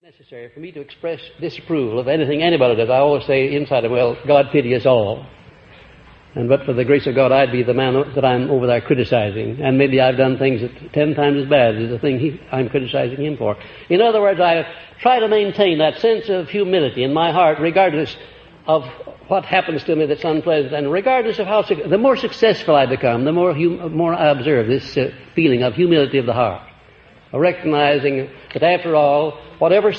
The lost recordings of Napoleon Hill are lectures as he personally promoted his philosophy of wealth and achievement, as written about in his famous books Law of Success and Think and Grow Rich.